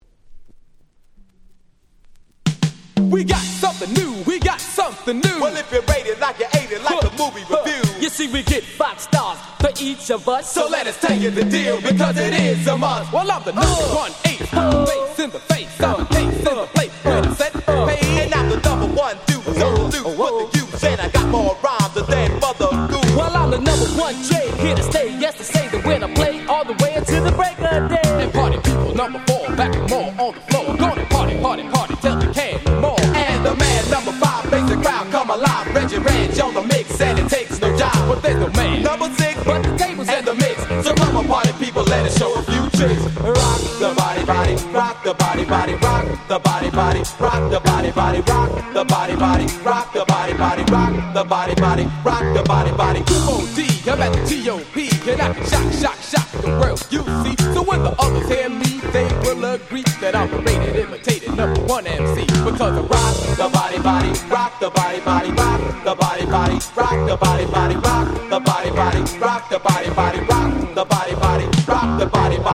80' Super Hip Hop Classics !!
問答無用のOld School Classics !!
オールドスクール 80's